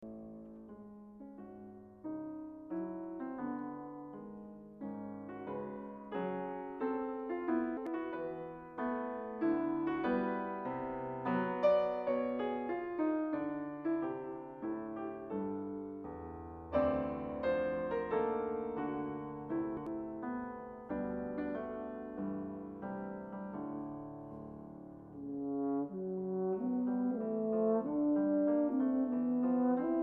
french horn
piano